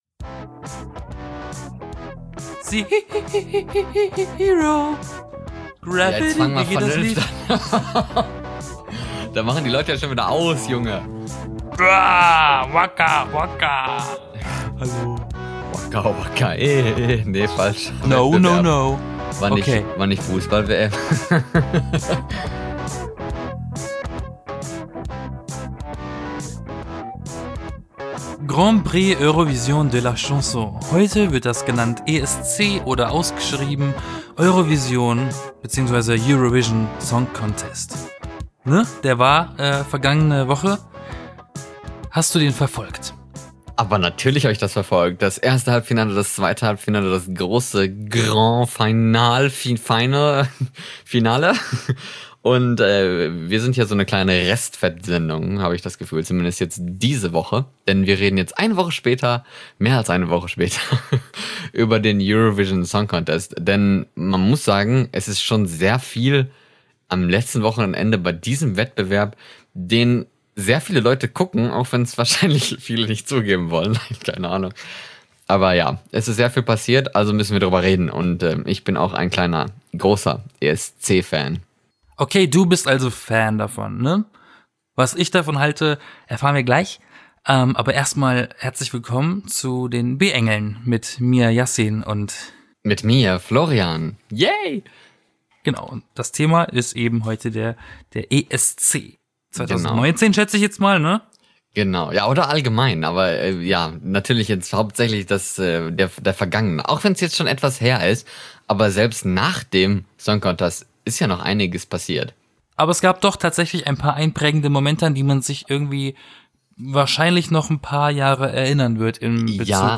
Der Podcast über Trends, News und den Alltagsgeschichten aus dem Leben von zwei Freunden aus Deutschland und Norwegen.